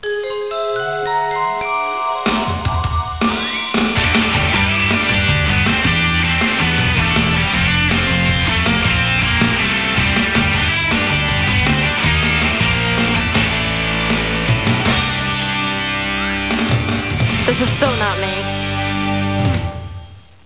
Here is the Theme Music to the Show.